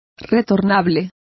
Complete with pronunciation of the translation of returnable.